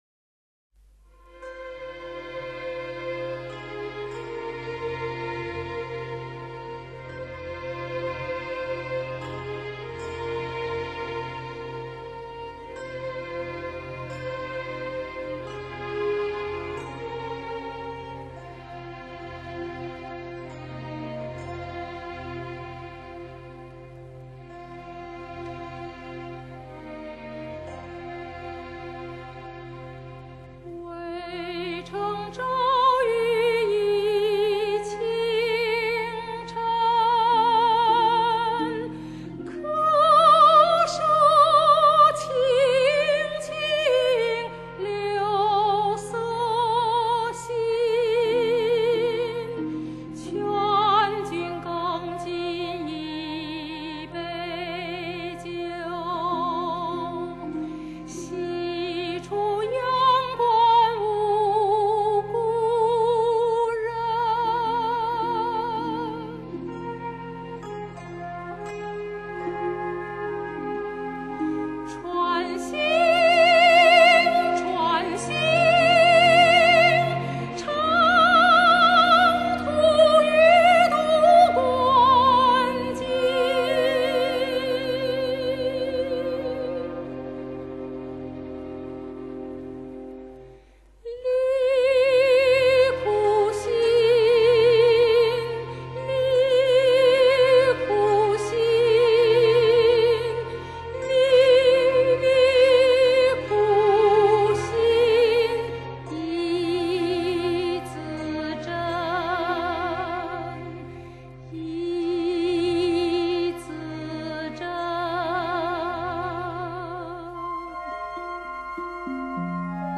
中国著名歌唱家与演奏家精心演绎，中国当代最著名古典诗词之迷人意韵。